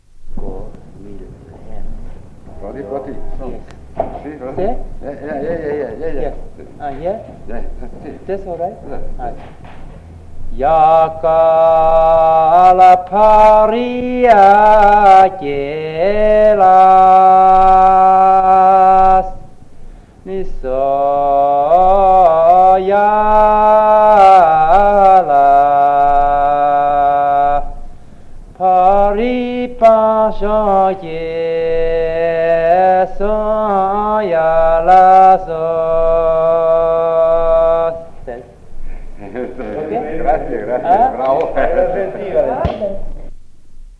Post 1975. 1 bobina di nastro magnetico.
A.2.4.28 - Canto nepalese (canta Tenzing Norgay in occasione del 6° Trento Film Festival, 1957)